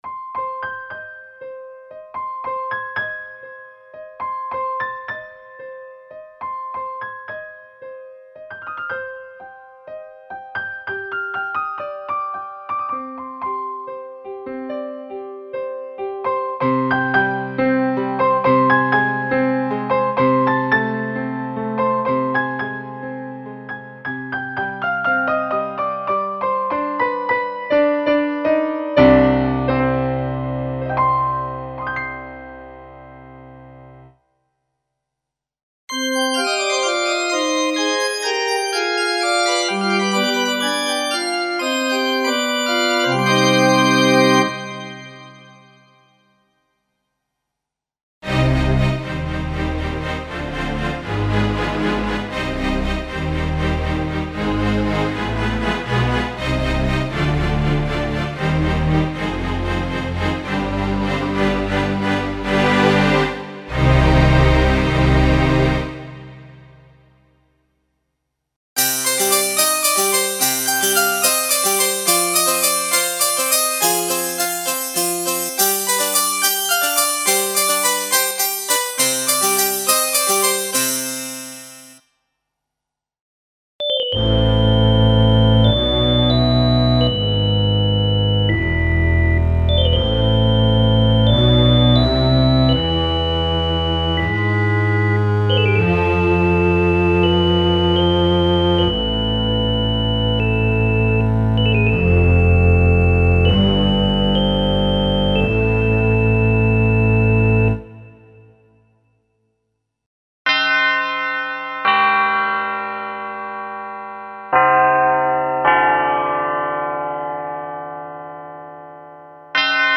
Collection of classical instruments (acoustic pianos, guitars and basses, pipe organs, strings, etc.), including selection of program layer & split combinations for live performance.
Info: All original K:Works sound programs use internal Kurzweil K2600 ROM samples exclusively, there are no external samples used.